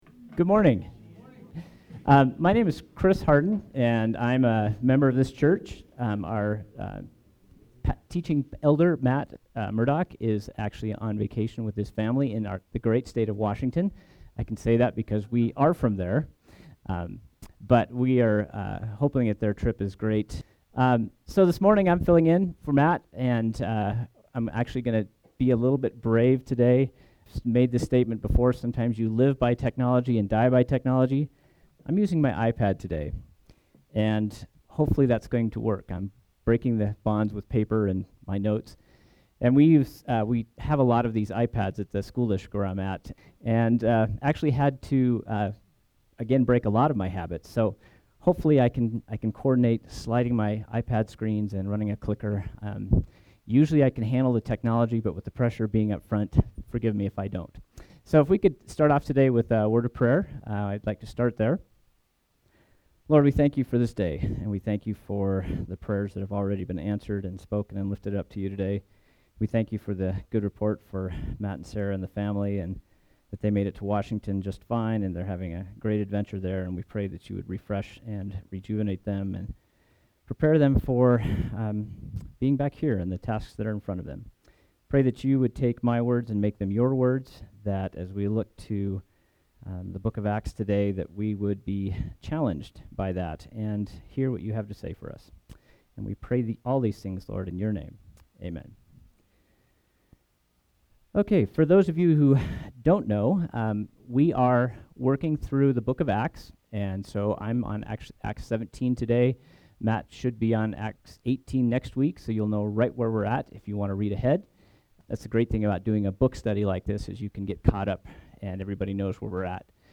SERMON: Paul in Athens